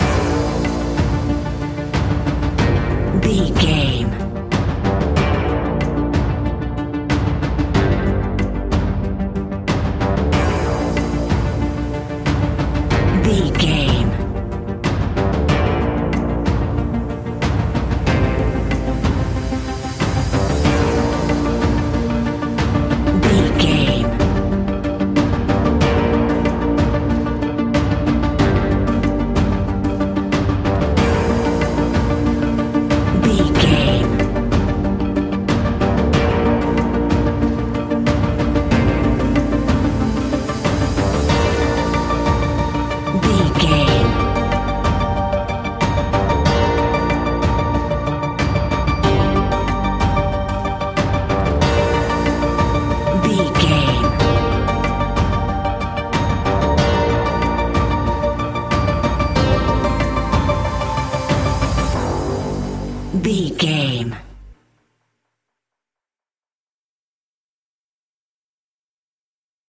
Aeolian/Minor
scary
tension
ominous
dark
eerie
synthesiser
drums
strings
ticking
electronic music